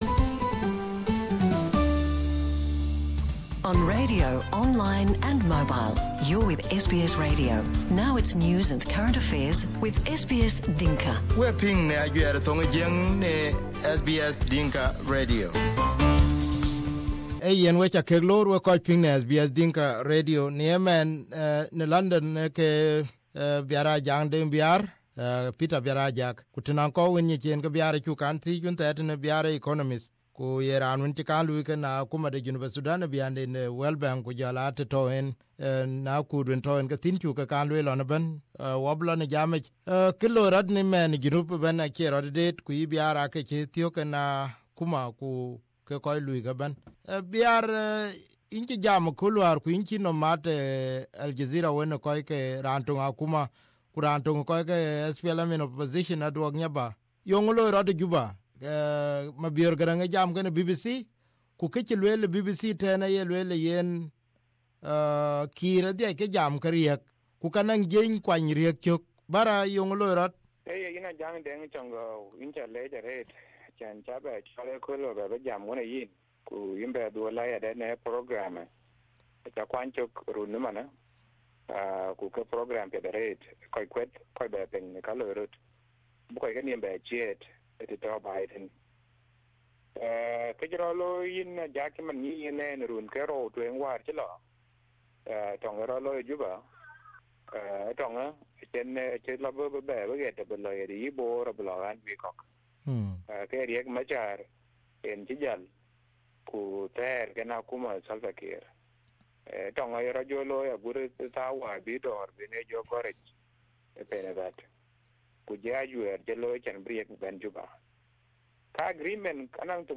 South Sudan seems to be going back to war after the July conflict. Listen to our interview